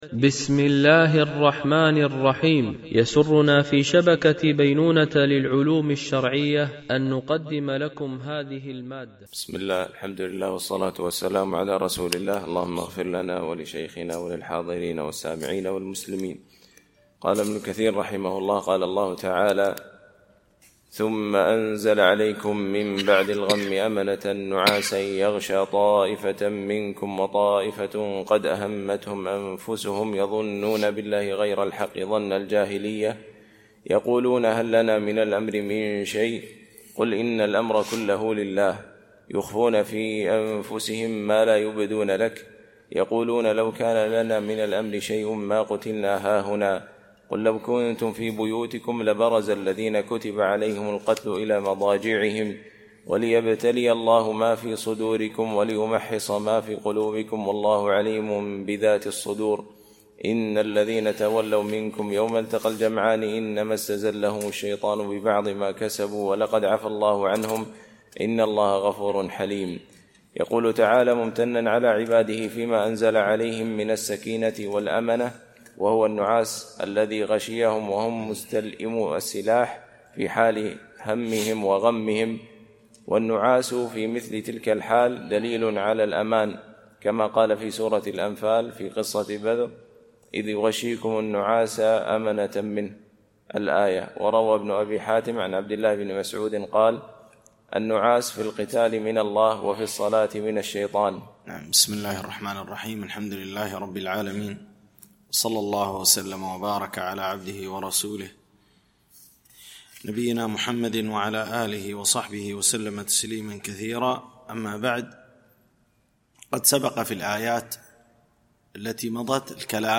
شرح مختصر تفسير ابن كثير (عمدة التفسير) ـ الدرس 120 (سورة آل عمران -الاية 154-164 )